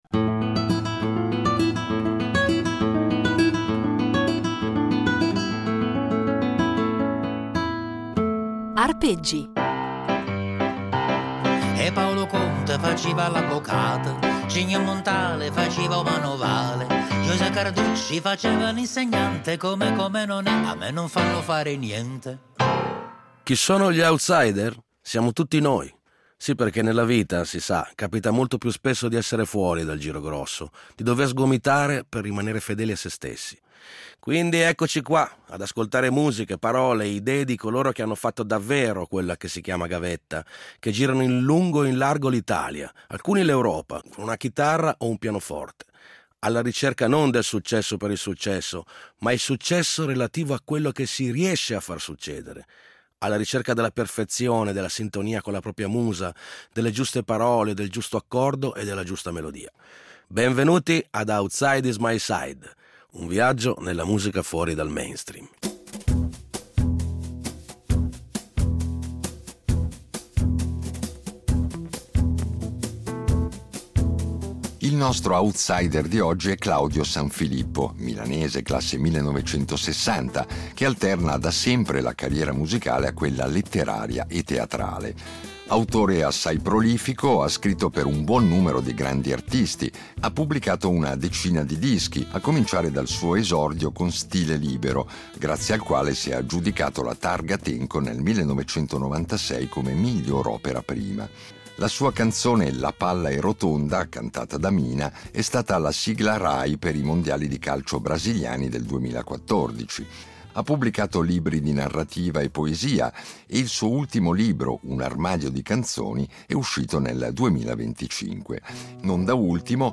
Sì, divertire, perché la musica d’autore è anche molto divertente e in queste 10 puntate ce ne accorgeremo, grazie alla disponibilità di dieci outsider o presunti tali, che ci offriranno dal vivo anche un assaggio della loro arte.